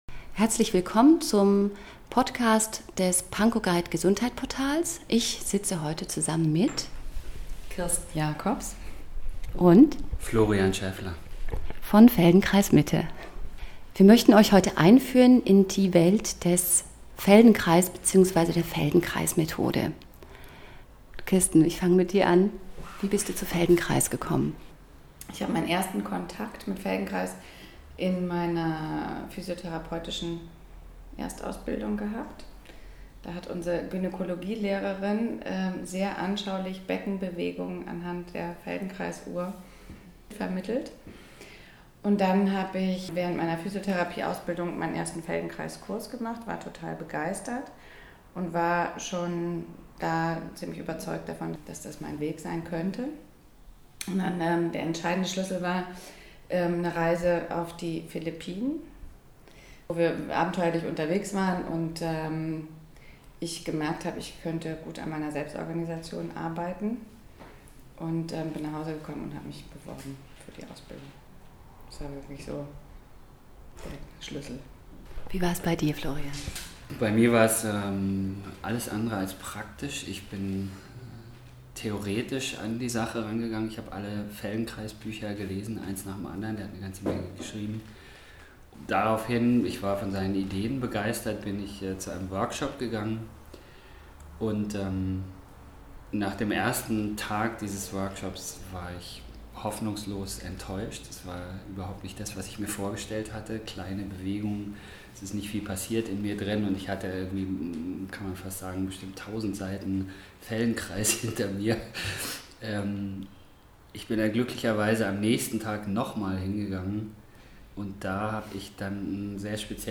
Das Feldenkrais Interview - Berlin Guide Gesundheit